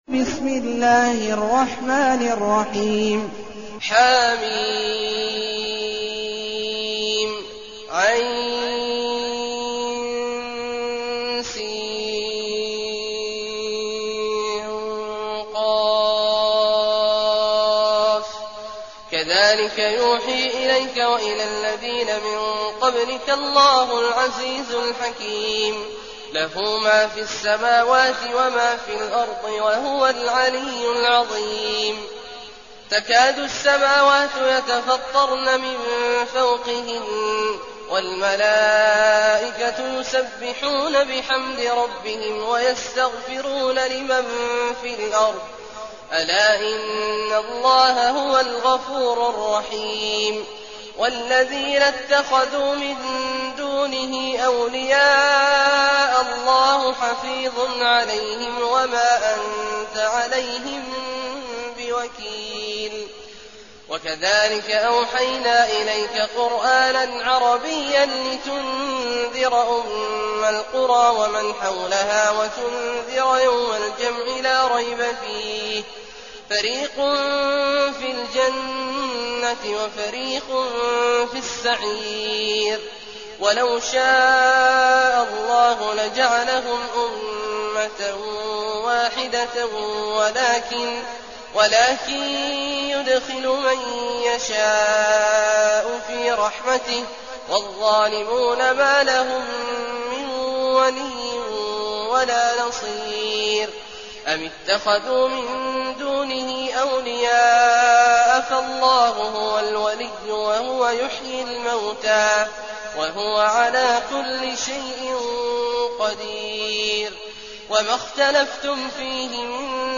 المكان: المسجد الحرام الشيخ: عبد الله عواد الجهني عبد الله عواد الجهني الشورى